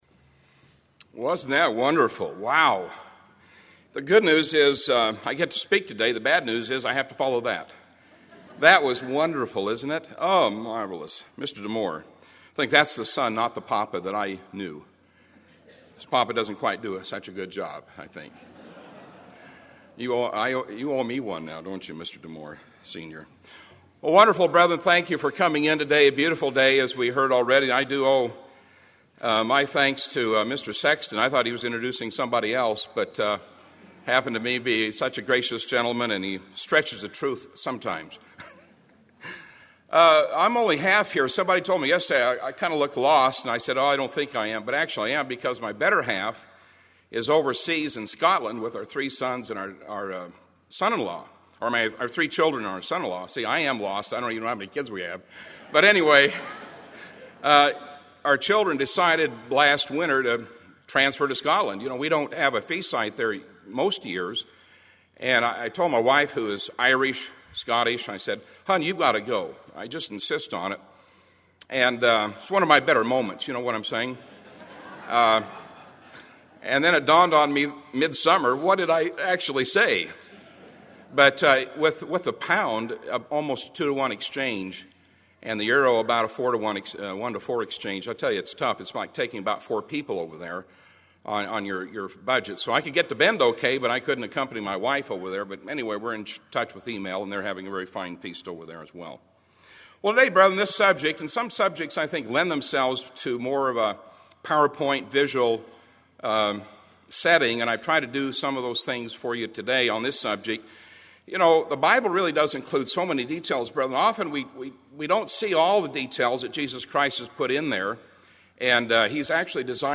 This sermon was given at the Bend, Oregon 2008 Feast site.